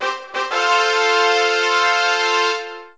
fanfare
Celebratory fanfare, suitable for an entry sound.